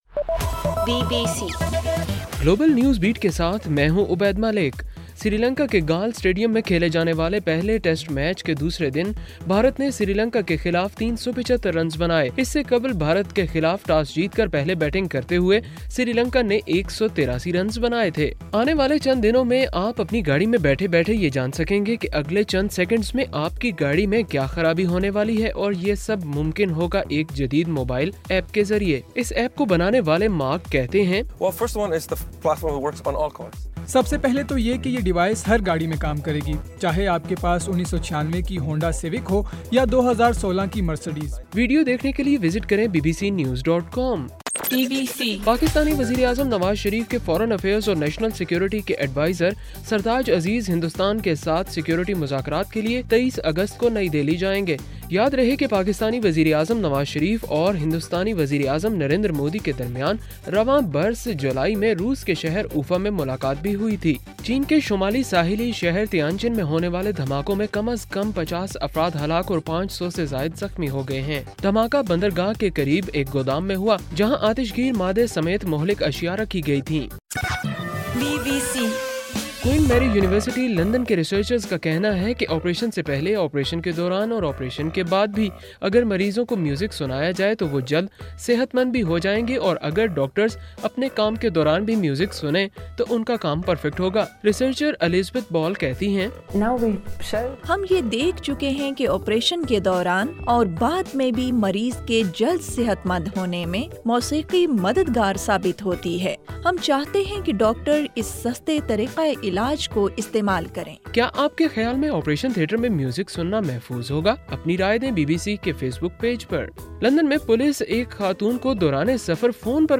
اگست 13: رات 9 بجے کا گلوبل نیوز بیٹ بُلیٹن